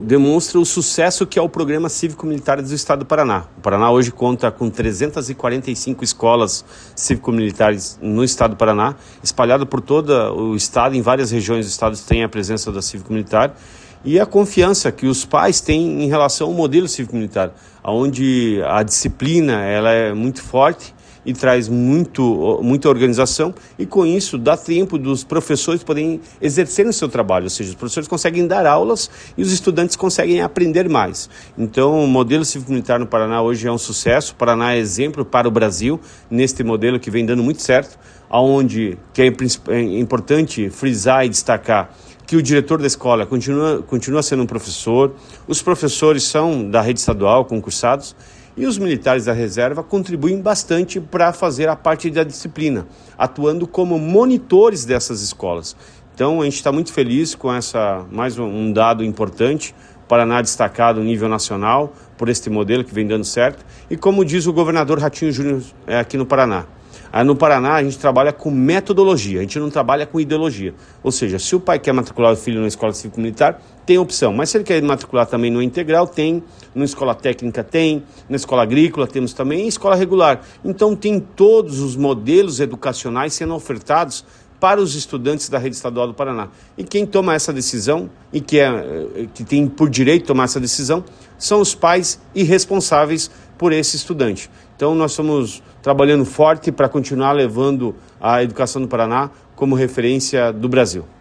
Sonora do secretário da Educação, Roni Miranda, sobre a alta procura pelos colégios cívico-militares da rede estadual de ensino